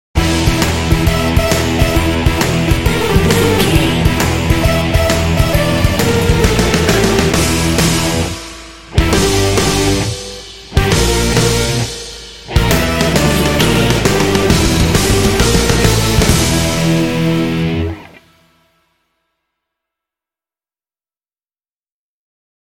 Aeolian/Minor
driving
funky
energetic
bass guitar
electric guitar
drums
synthesiser
alternative rock